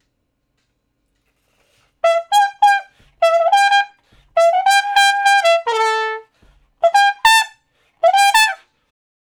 084 Trump Shuffle (E) 03.wav